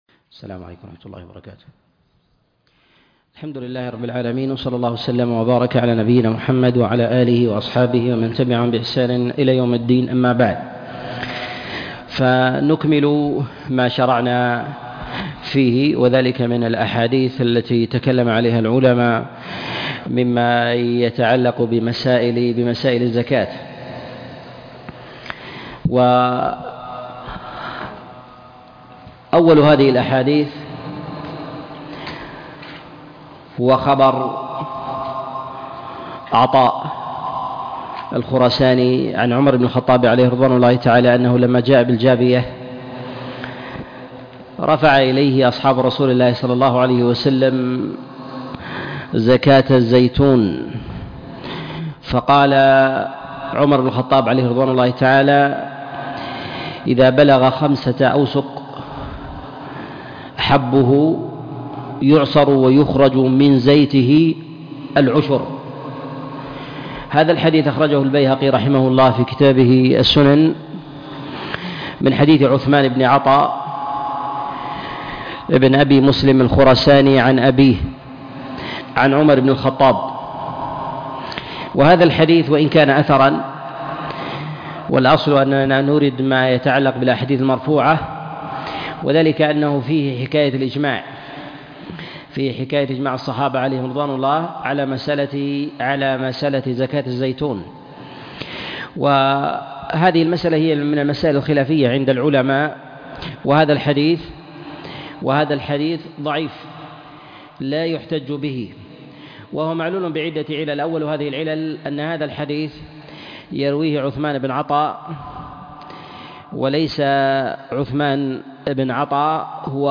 الأحاديث المعلة في الزكاة الدرس 4